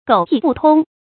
狗屁不通 注音： ㄍㄡˇ ㄆㄧˋ ㄅㄨˋ ㄊㄨㄙ 讀音讀法： 意思解釋： 指責別人說話或文章極不通順 出處典故： 清 石玉昆《三俠五義》第35回：「柳老賴婚狼心推測，馮生聯句 狗屁不通 。」